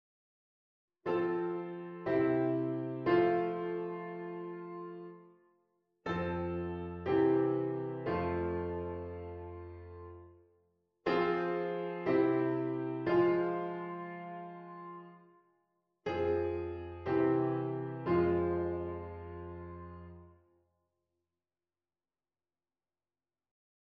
harmonisatie van 'bovenstemfiguurtjes' met I -V -I (in de meeste gevallen: met gebruikmaking van melodische verbinding)
a. 3^ 2^ 1^